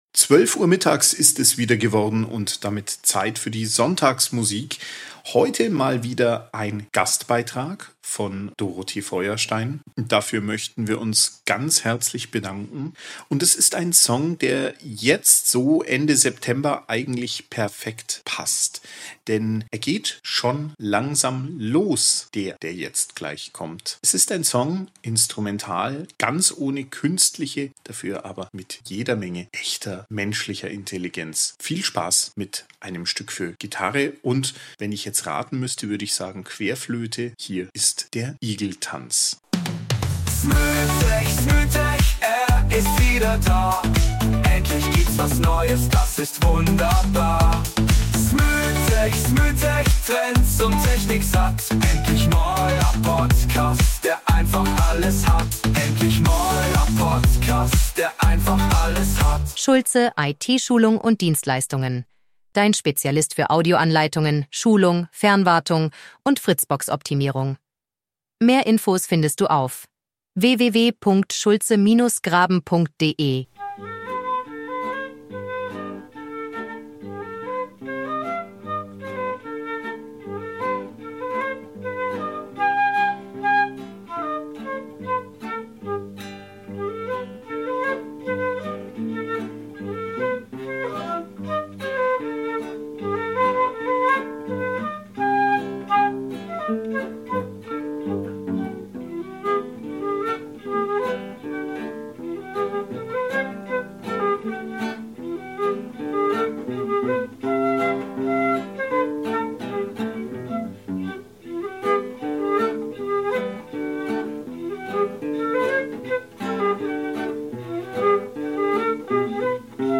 fröhlichen
Instrumentalstück auf die bunten Tage des Herbstes ein.